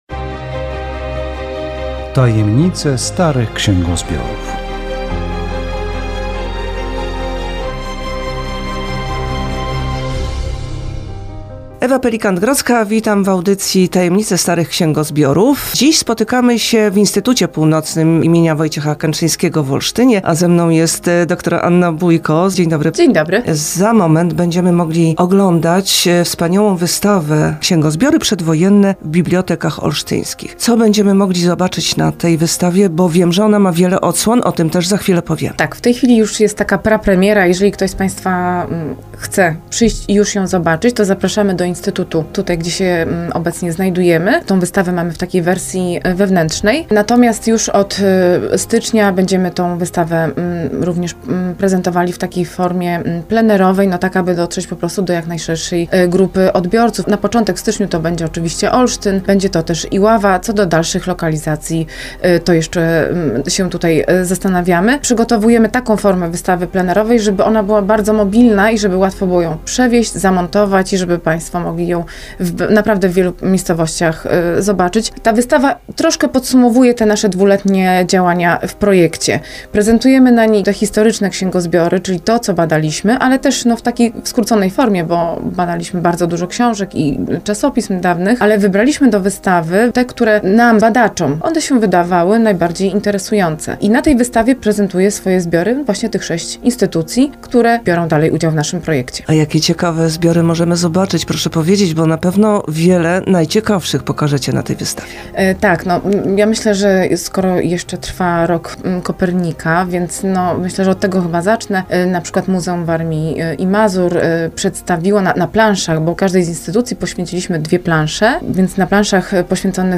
Audycja radiowa "Tajemnice starych księgozbiorów" zawierająca zapowiedź wernisażu wystawy "Księgozbiory przedwojenne w bibliotekach olsztyńskich".